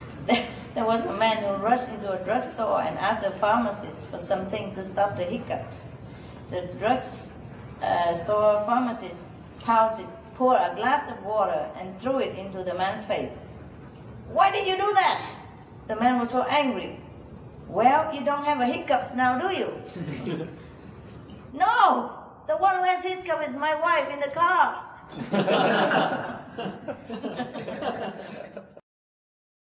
อาจารย์เล่าเรื่องตลก
ปราศรัยโดยอนุตราจารย์ชิงไห่ ฟลอริด้า สหรัฐอเมริกา 4 กุมภาพันธ์ 2546